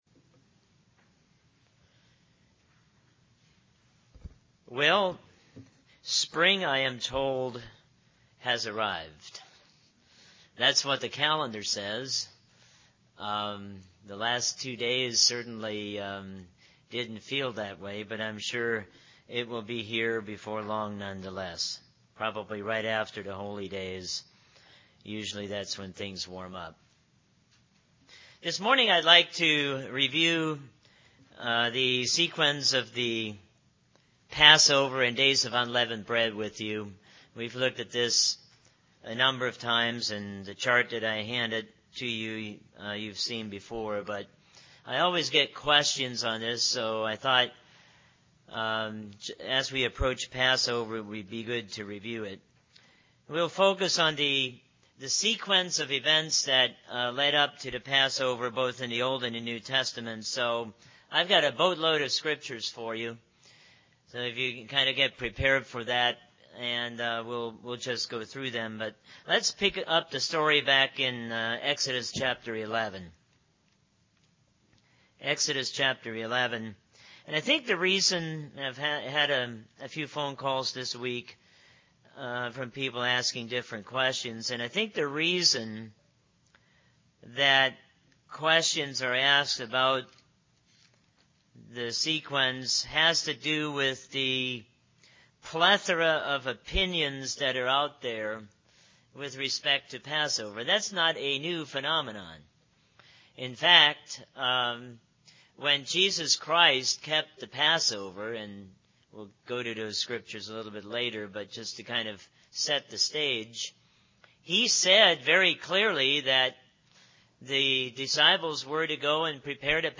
Given in North Canton, OH
Reference Chart UCG Sermon Studying the bible?